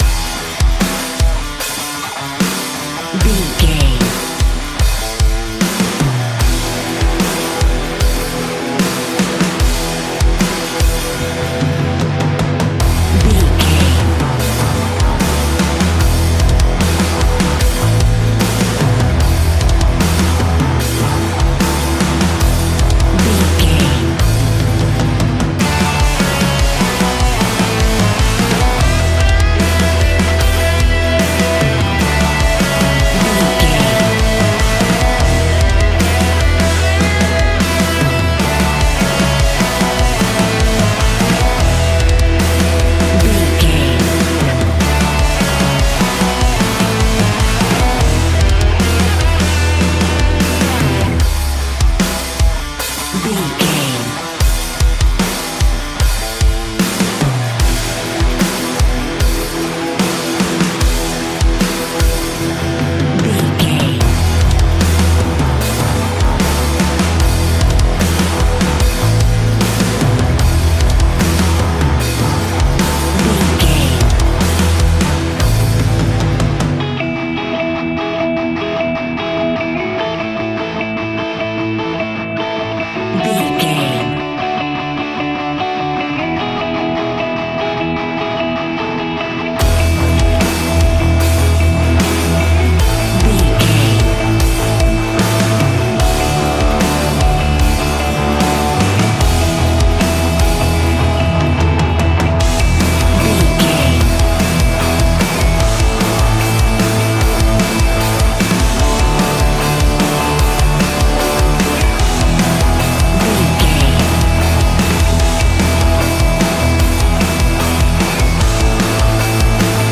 Ionian/Major
E♭
hard rock
heavy metal
instrumentals